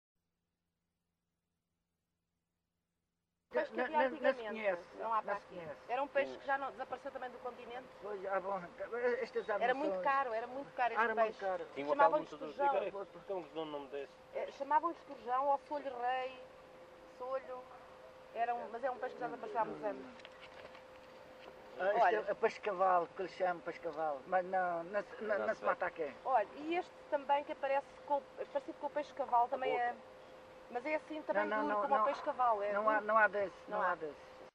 LocalidadeCâmara de Lobos (Câmara de Lobos, Funchal)